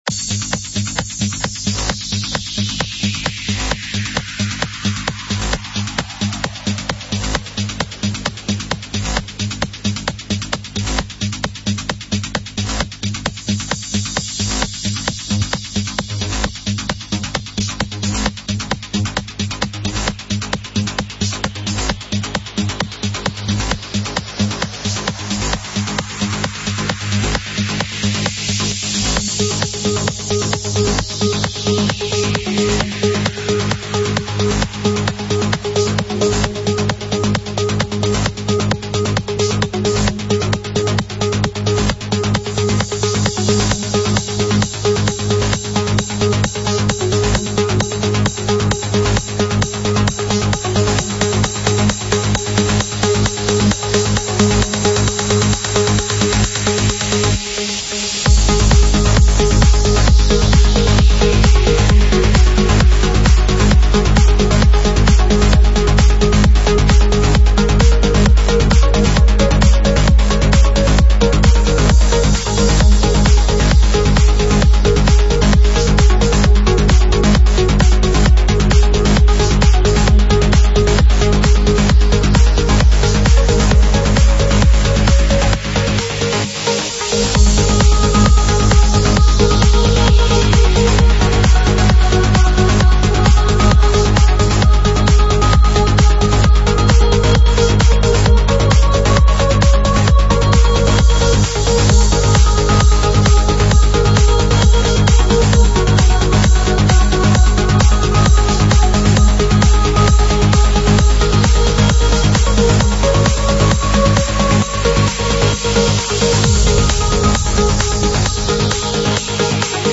Стиль: Trance / Progressive Trance